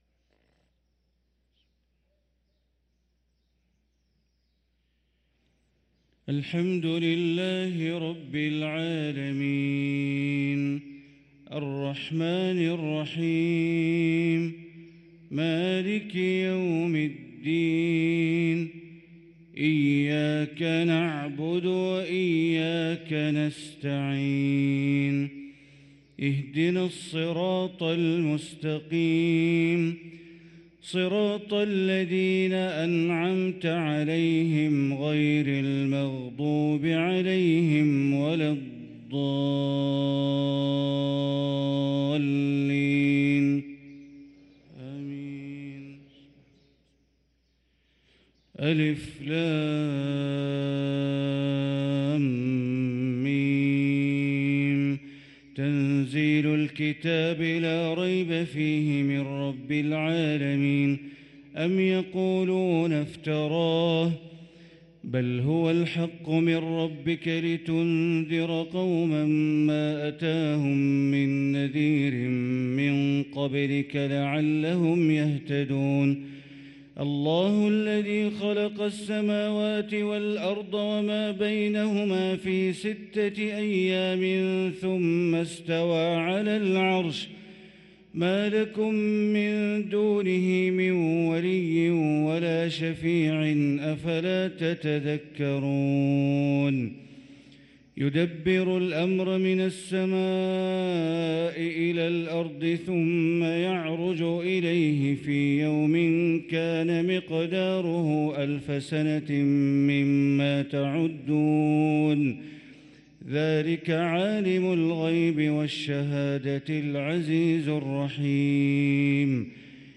صلاة الفجر للقارئ بندر بليلة 2 جمادي الآخر 1445 هـ
تِلَاوَات الْحَرَمَيْن .